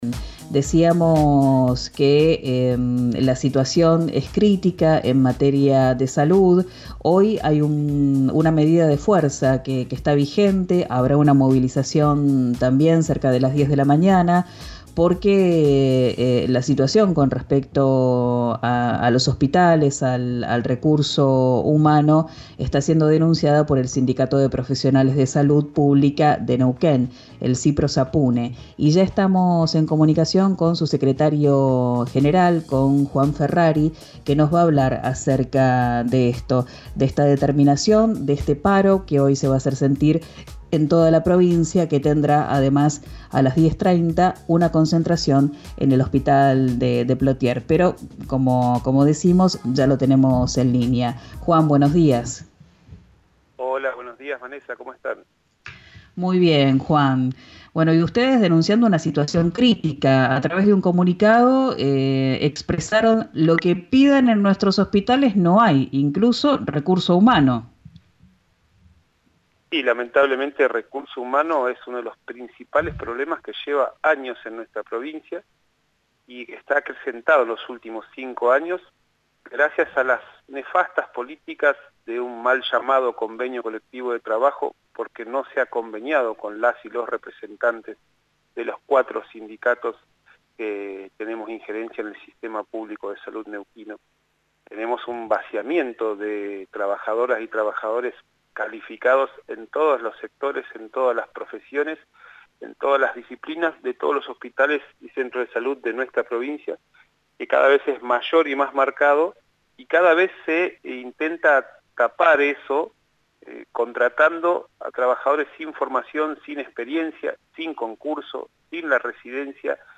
En diálogo con el programa “Arranquemos” de RIO NEGRO RADIO, además apuntó duramente contra el Convenio Colectivo de Trabajo que firmó el Gobierno con el gremio ATE.